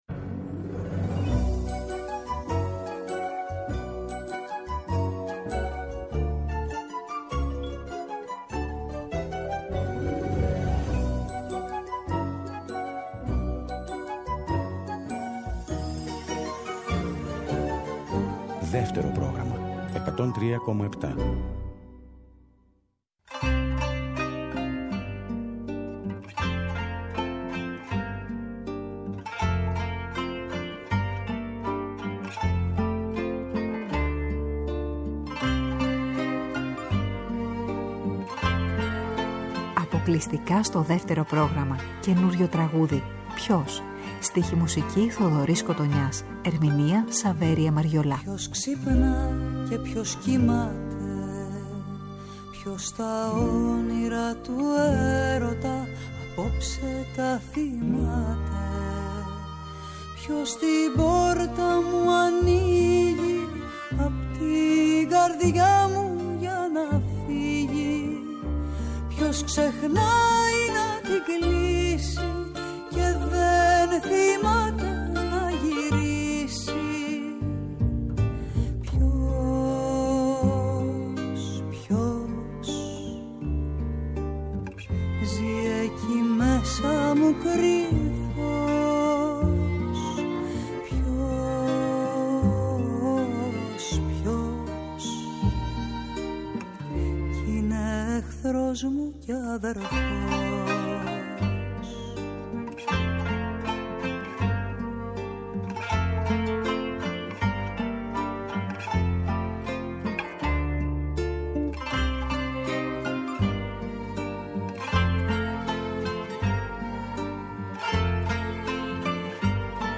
ΔΕΥΤΕΡΟ ΠΡΟΓΡΑΜΜΑ Συνεντεύξεις